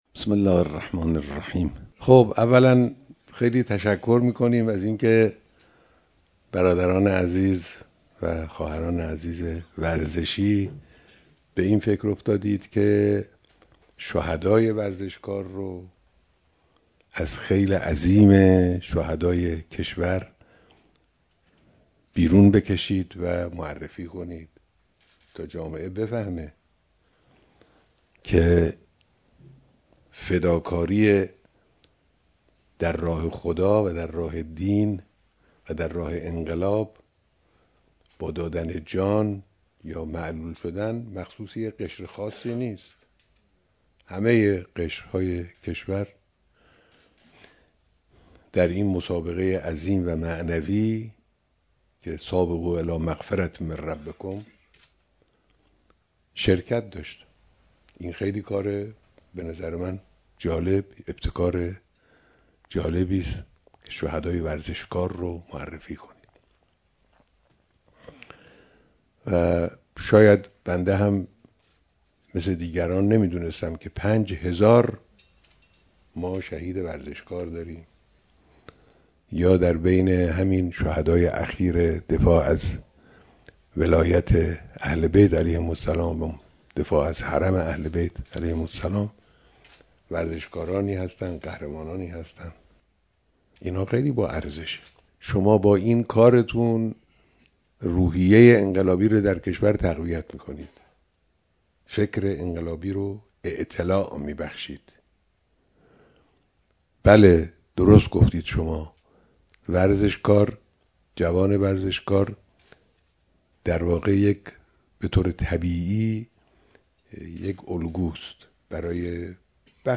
بیانات در دیدار دست‌اندرکاران کنگره شهدای ورزش کشور